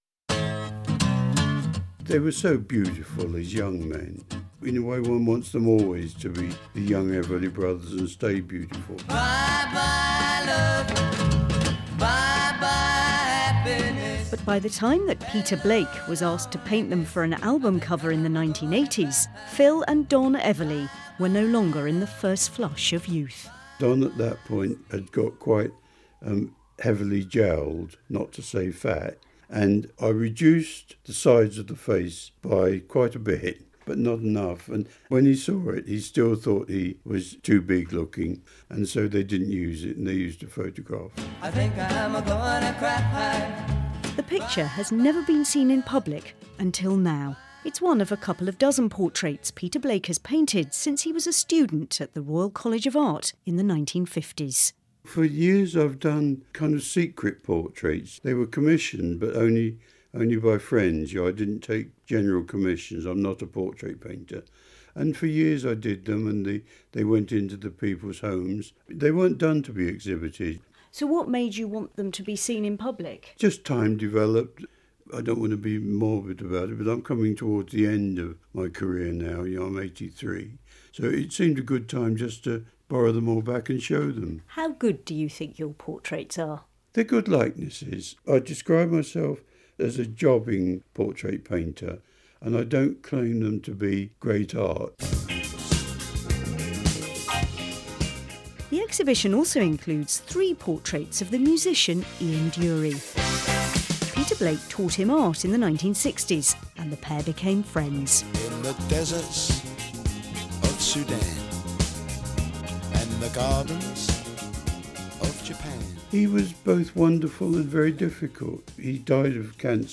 report for BBC Radio 4's Today programme.